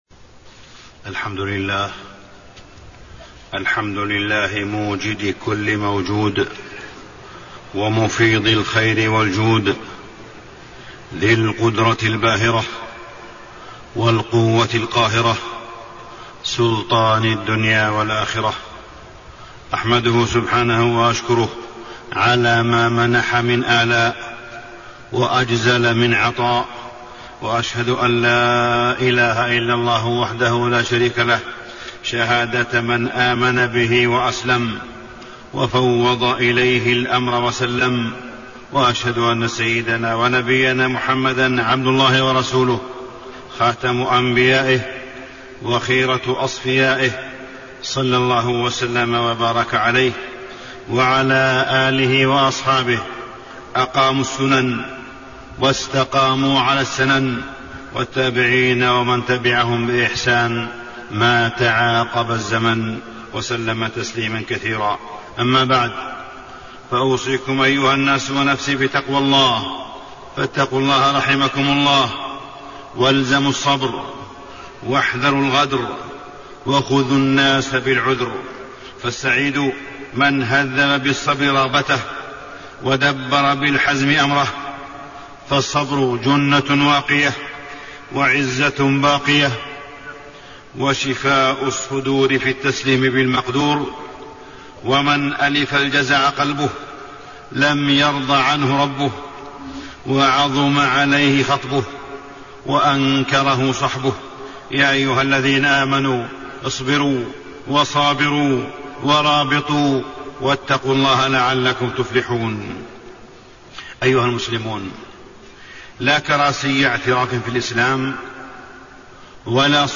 تاريخ النشر ٩ ربيع الثاني ١٤٣٣ هـ المكان: المسجد الحرام الشيخ: معالي الشيخ أ.د. صالح بن عبدالله بن حميد معالي الشيخ أ.د. صالح بن عبدالله بن حميد ستر الله على عباده